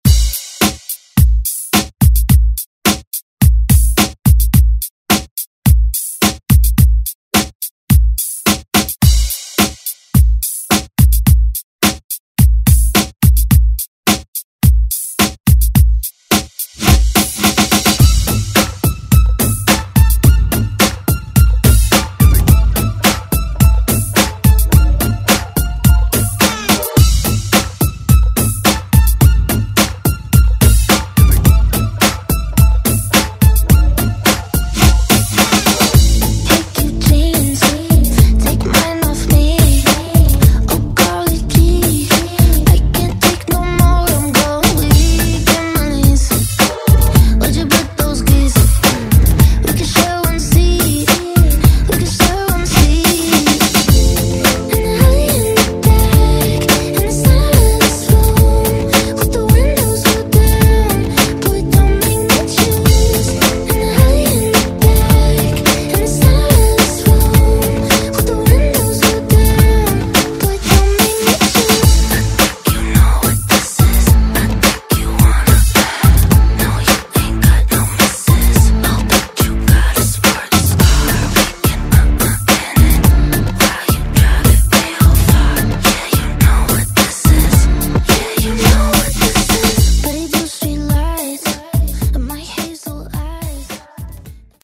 Genres: RE-DRUM , TOP40
Clean BPM: 107 Time